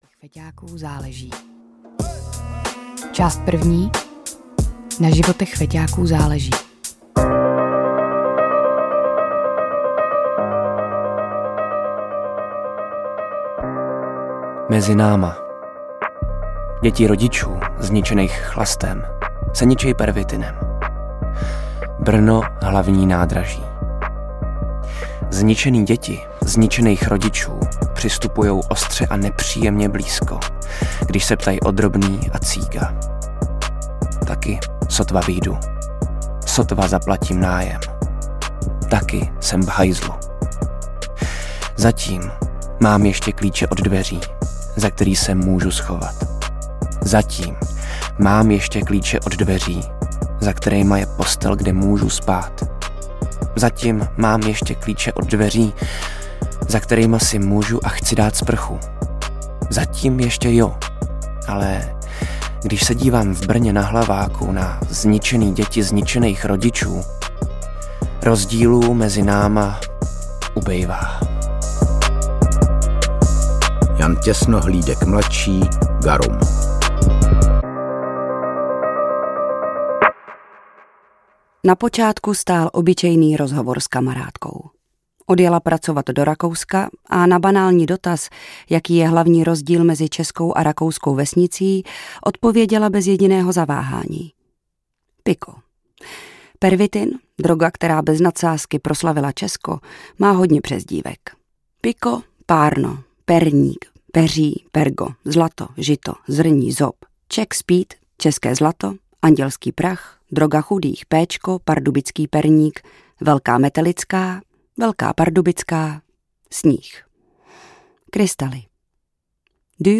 Piko audiokniha
Ukázka z knihy